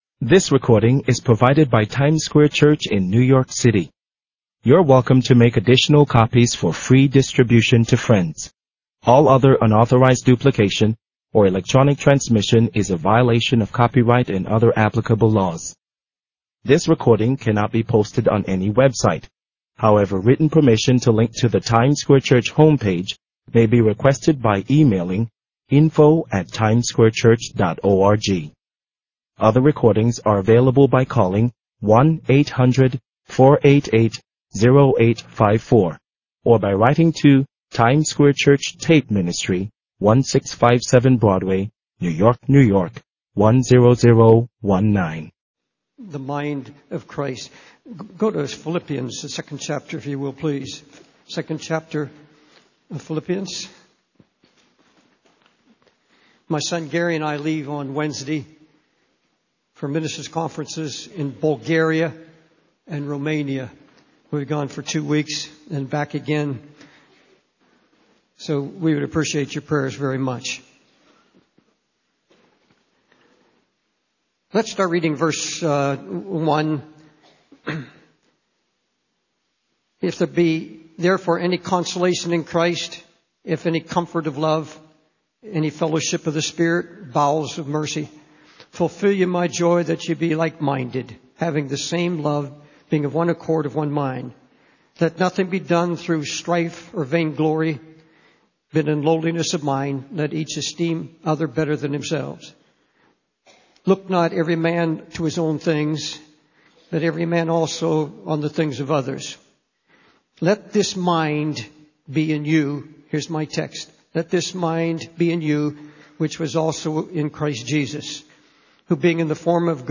In this sermon, the speaker reflects on their personal journey of seeking God and being called to work with gangs and drug addicts.